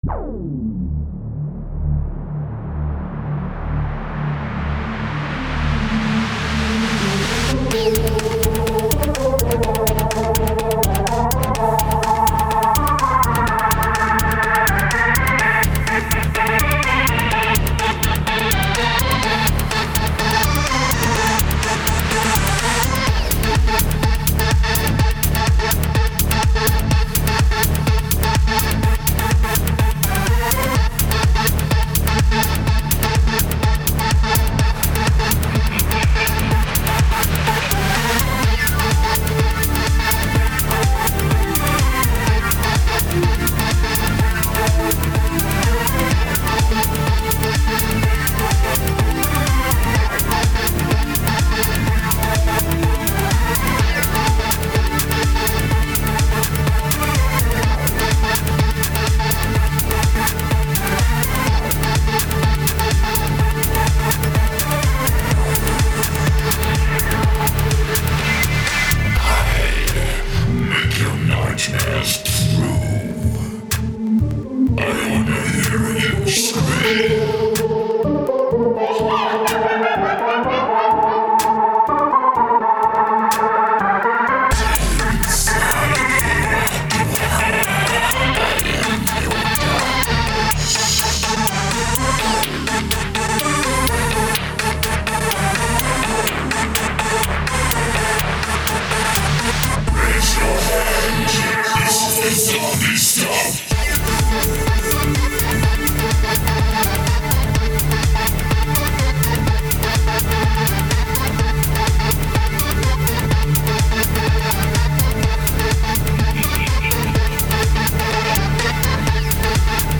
31.45 Друзья, хотел сначала написать Техно, но в итоге вышел Хард Стиль. Вашему вниманию второй трек на тему Хеллоуина.